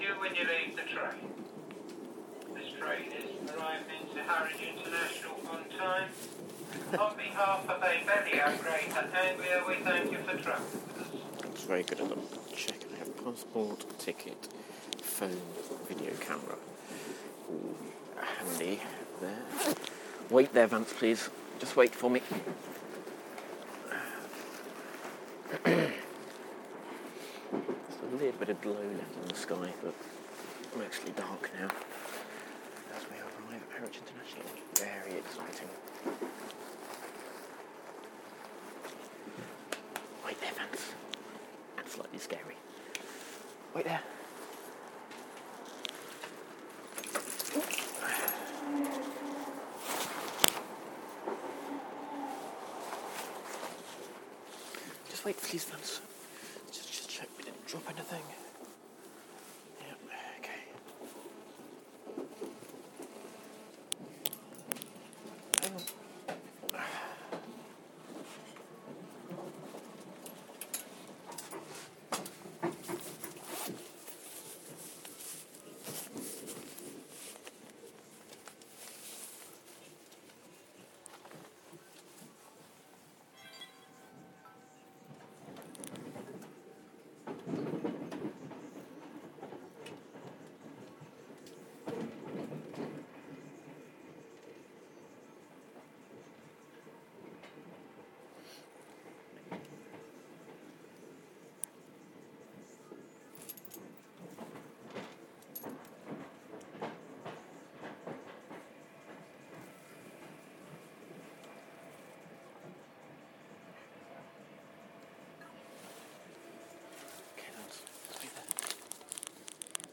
Arrival by train at Harwich International station to catch the overnight ferry to the Hook of Holland